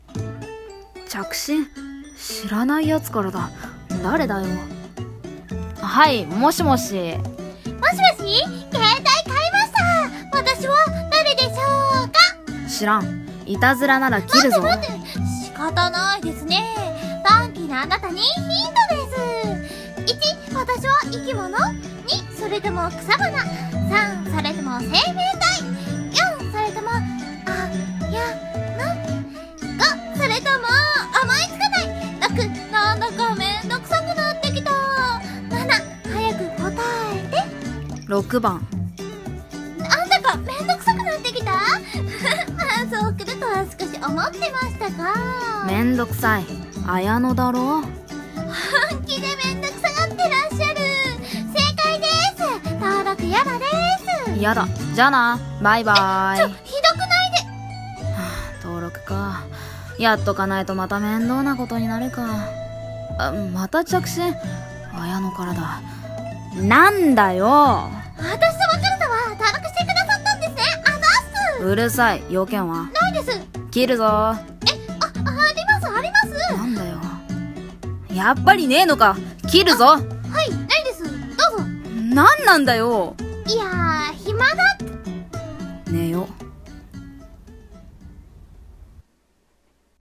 【声劇台本】いたずら電話w